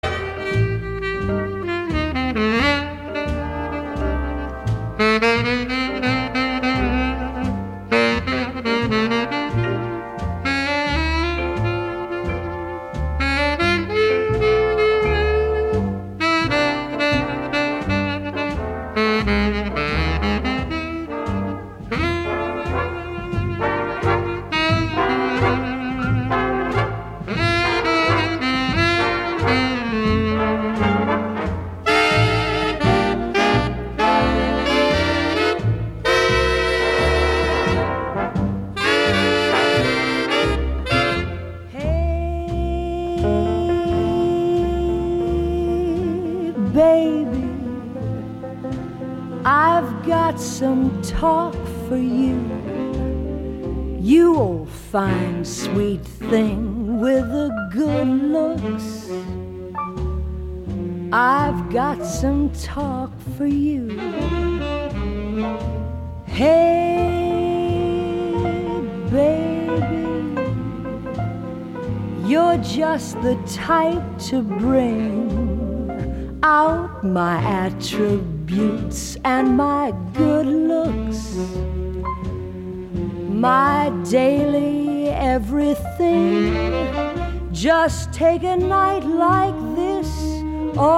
頂尖的樂團、一流的編曲，加上動人的演唱